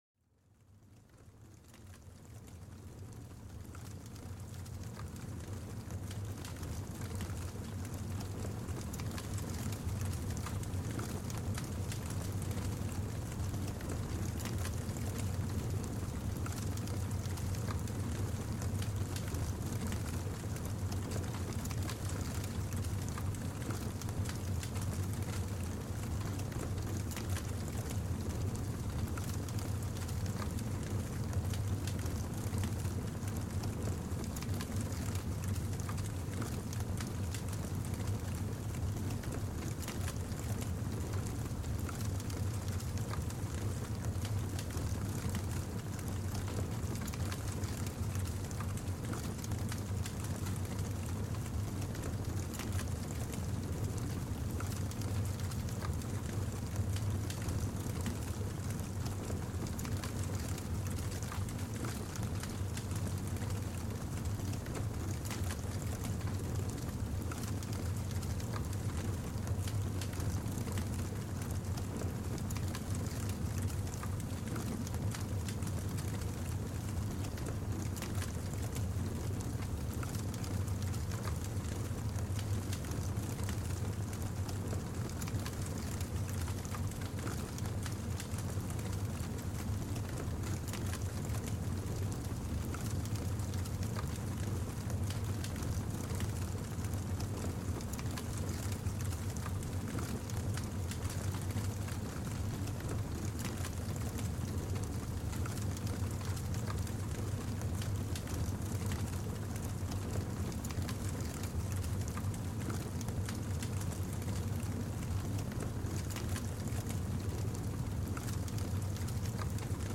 Descubre la magia del crepitar del fuego en este episodio único, donde cada chispa cuenta una historia. Déjate envolver por el calor reconfortante del fuego, un refugio sonoro para el alma en busca de serenidad.